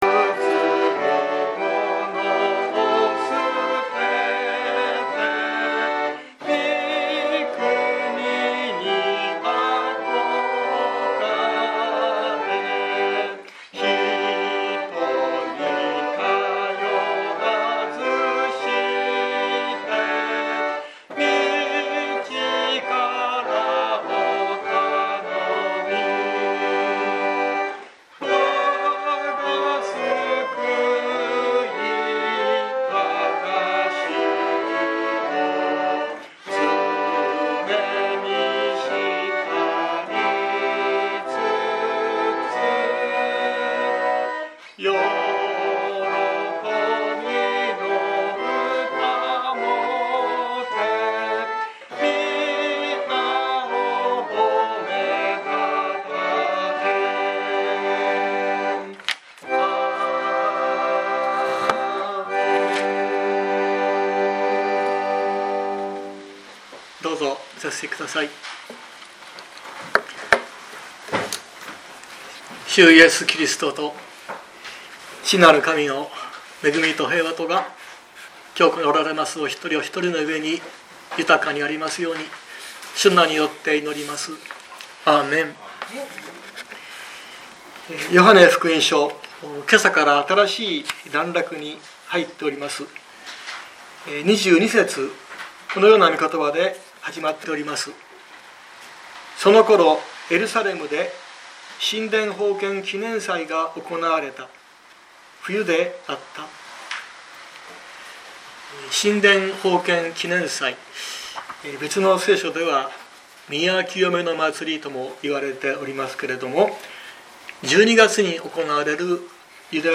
2023年03月26日朝の礼拝「永遠の命の約束」熊本教会
説教アーカイブ。